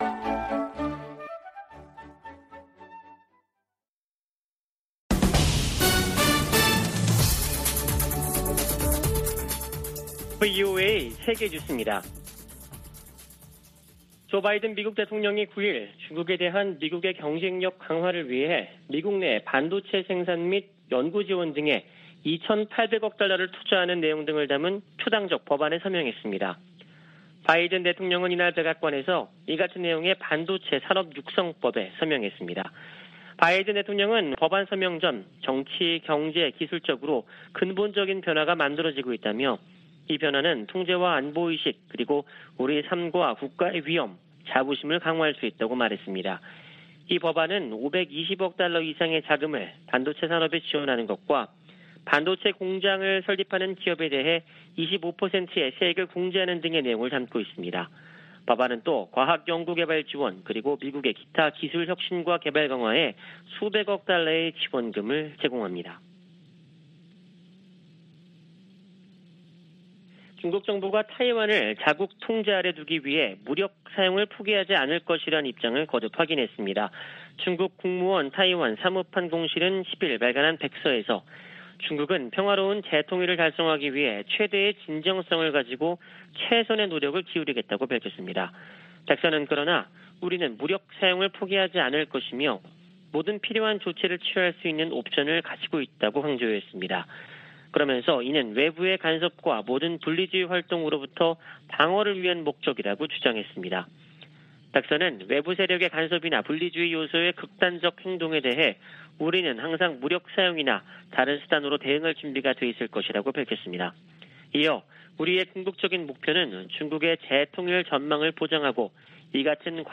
VOA 한국어 아침 뉴스 프로그램 '워싱턴 뉴스 광장' 2022년 8월 11일 방송입니다. 박진 한국 외교부 장관이 왕이 중국 외교부장과의 회담에서 사드 3불은 중국과의 합의나 약속이 아니라는 점을 분명히 했다고 밝혔습니다. 북한이 올해 다수의 가상화폐 탈취 사건에 관여한 것으로 지목되는 가운데 피해와 위협이 증가할 것이라는 분석이 나왔습니다. 서울 유엔인권사무소가 북한군에 피살된 한국 해양수산부 공무원 사건 진상규명을 강조했습니다.